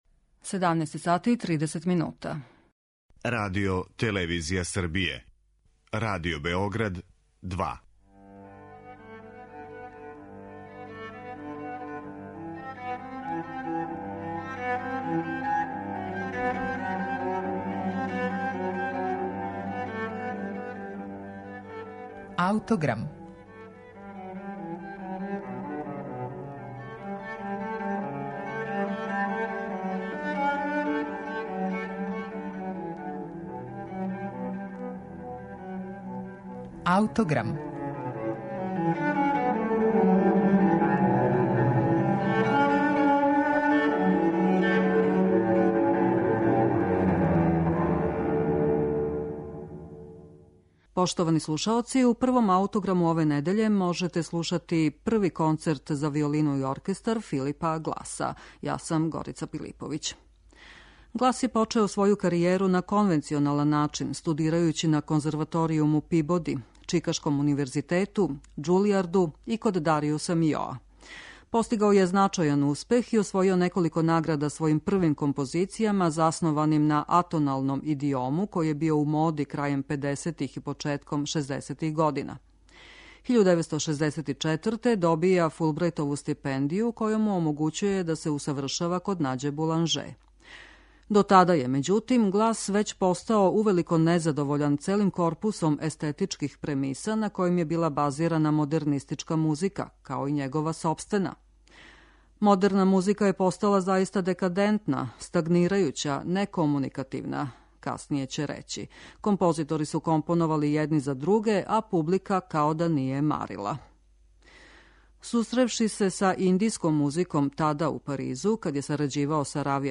МИНИМАЛИСТА И ТРАДИЦИОНАЛНА ФОРМА
Концерт за виолину и оркестар